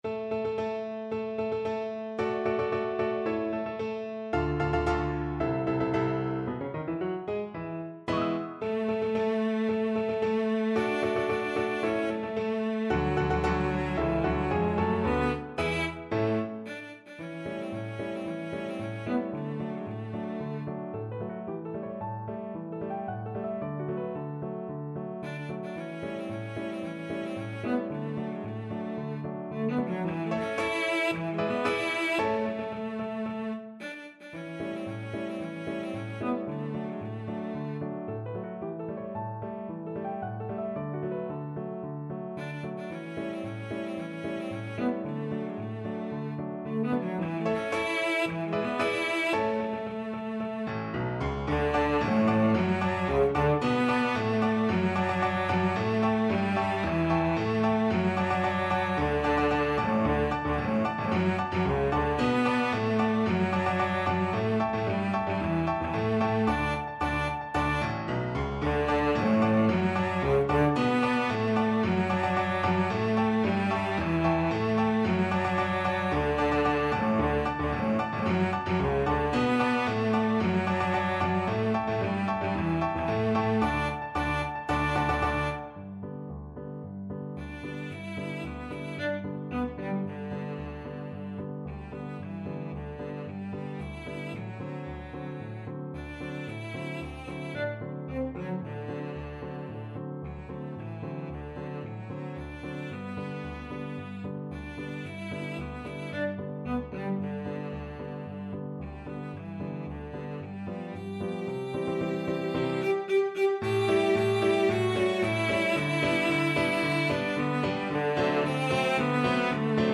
Cello
2/4 (View more 2/4 Music)
~ = 112 Introduction
A3-G5
D major (Sounding Pitch) (View more D major Music for Cello )
Classical (View more Classical Cello Music)